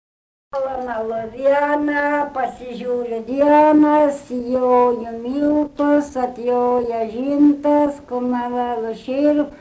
Milling songs
They suggest the hum of the millstones as well as the rhythm of the milling.
Milling songs have no traditional melodies, but they are characteristically slow, composed, the melodic rhythm varies little.